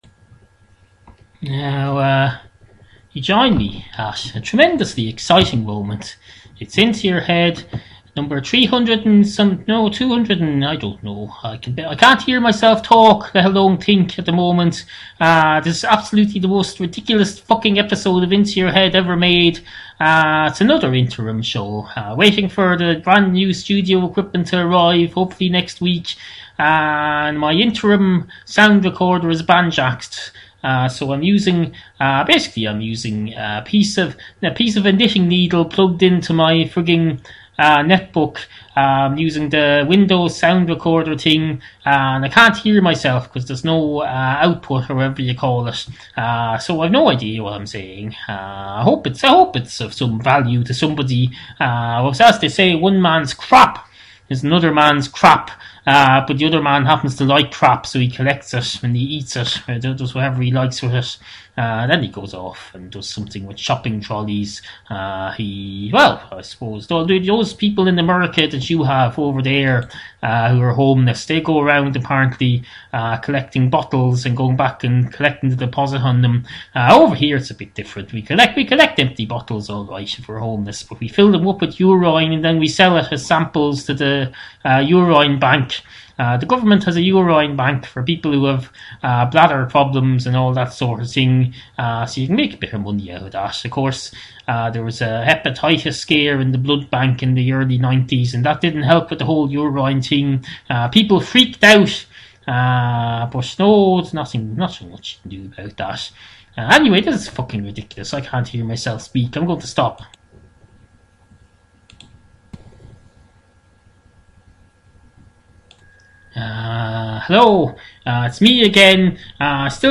It says here that this was another interim show, with unfeasibly bad sound quality, as we still waited in joyful hope for new equipment or something.